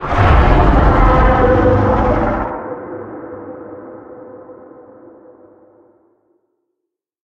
File:Sfx creature hiddencroc callout 05.ogg - Subnautica Wiki
Sfx_creature_hiddencroc_callout_05.ogg